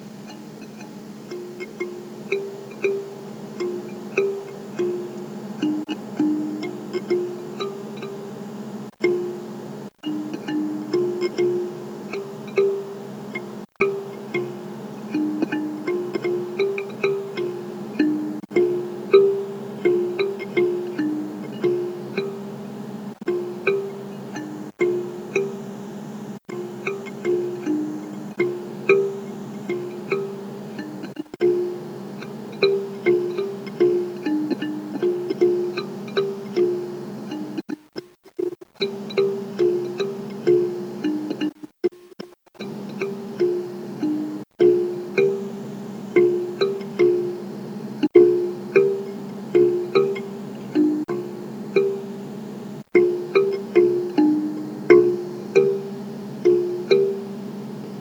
Gourd Mbira
A test device therefore, and also to see what would and would not work mbira sound-wise
The sound seemed ok to me, at least in the realm of plausible, and the design with a few modifications would be very tunable.